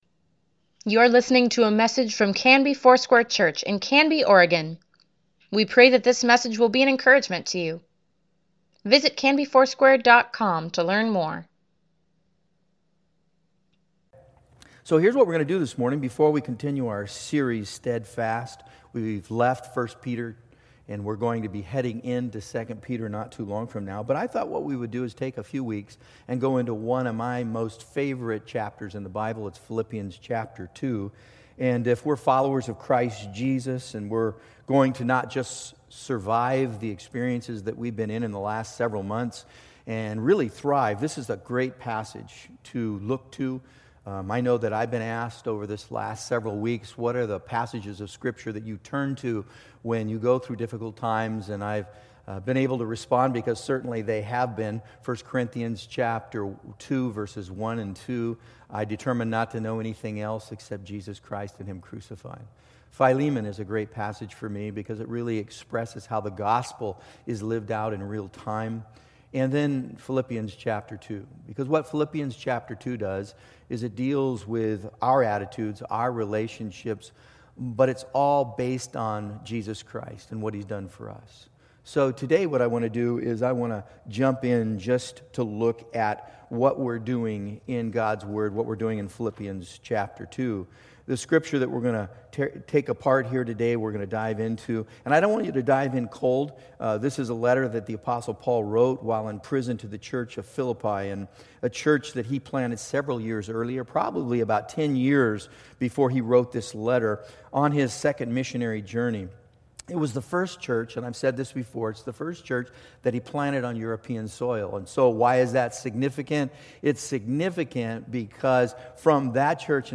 Weekly Email Water Baptism Prayer Events Sermons Give Care for Carus Who Is Jesus September 20, 2020